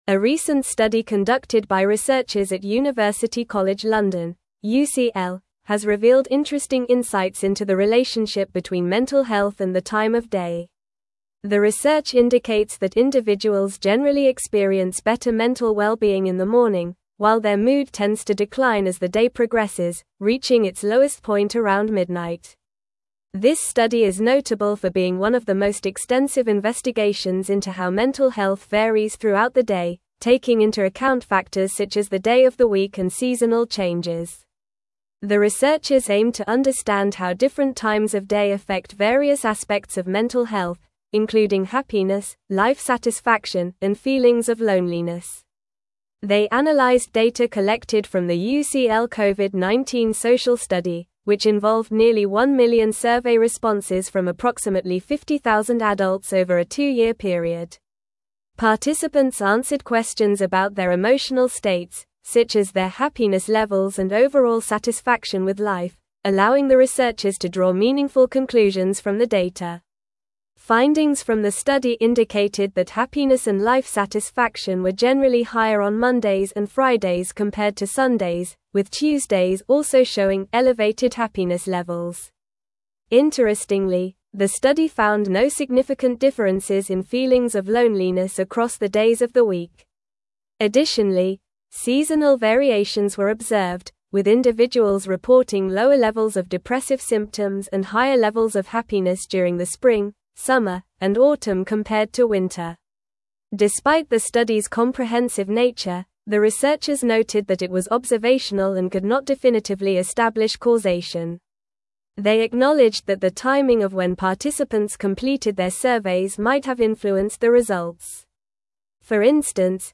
Normal
English-Newsroom-Advanced-NORMAL-Reading-Mental-Health-Declines-Throughout-the-Day-Study-Finds.mp3